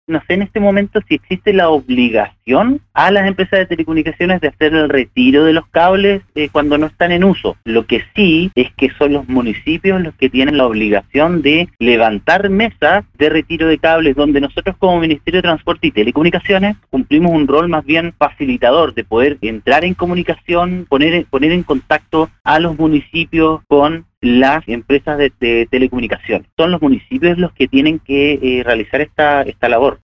El Seremi de Transporte, Pablo Joost, indicó en conversación con Radio SAGO que -a pesar de desconocer si existe una normativa vigente que faculte a las municipalidades- es la responsabilidad de la casa edilicia realizar mesas de trabajo para generar  soluciones a las problemáticas causadas por el cableado suelto.